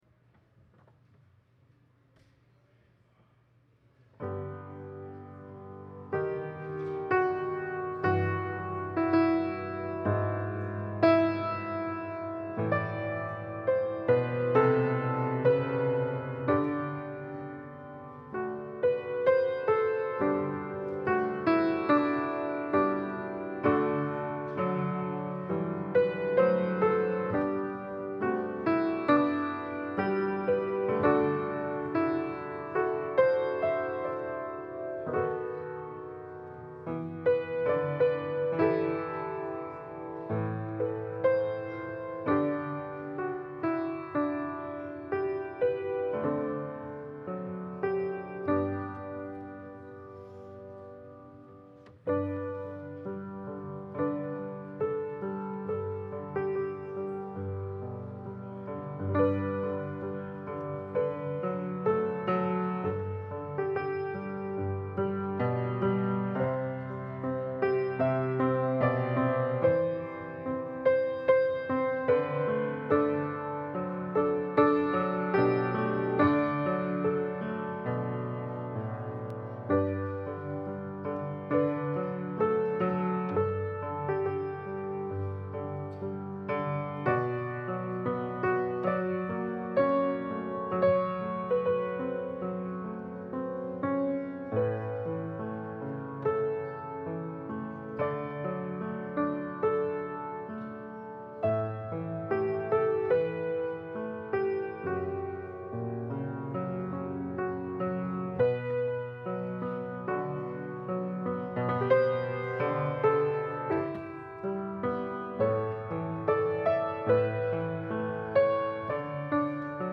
Scriptures and sermon from St. John’s Presbyterian Church on Sunday
Passage: 2 Peter 1: 16-21 Service Type: Holy Day Service Scriptures and sermon from St. John’s Presbyterian Church on Sunday